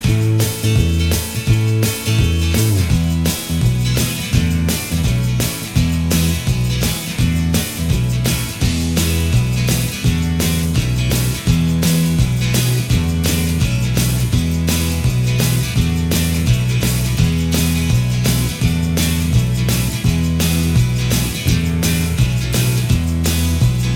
Minus Lead Guitar Rock 3:42 Buy £1.50